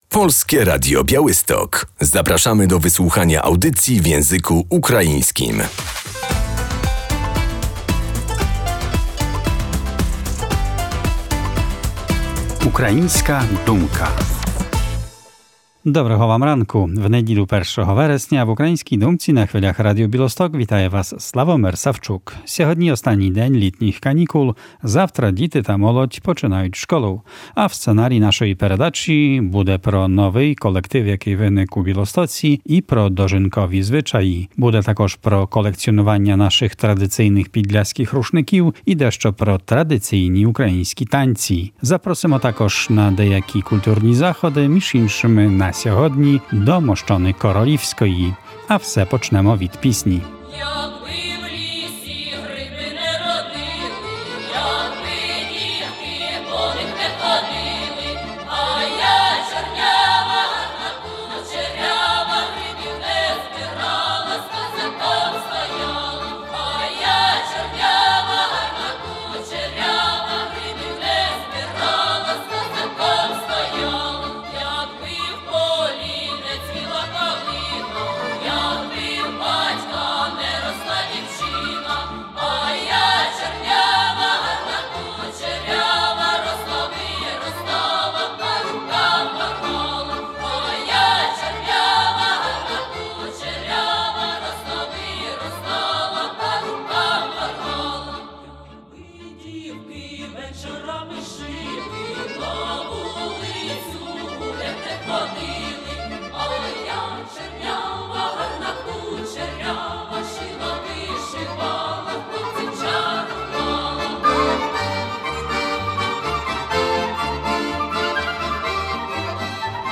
Na próbie zespołu Rosawa 01.09.2024